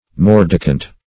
Search Result for " mordicant" : The Collaborative International Dictionary of English v.0.48: Mordicant \Mor"di*cant\, a. [L. mordicans, p. pr. of mordicare to bite, fr. mordere: cf. F. mordicant.]